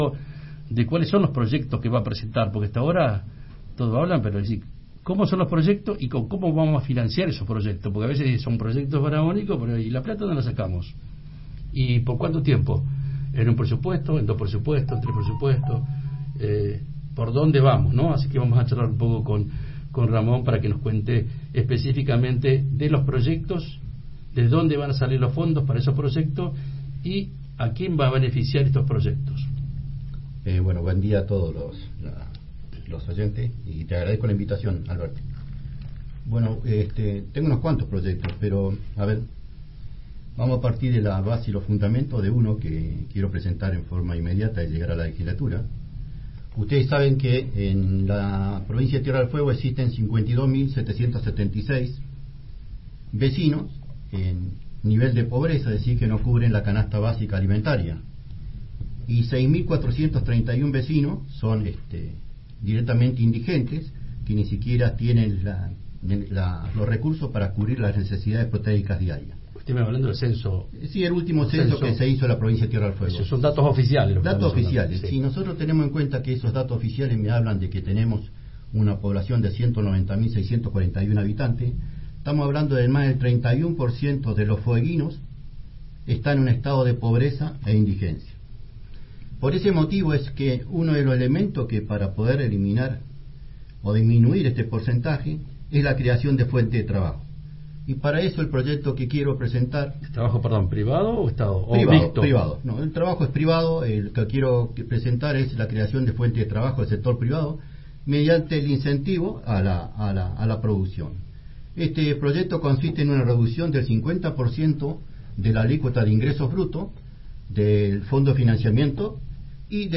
expuso sus propuestas por Radio Universidad 93.5 y Provincia 23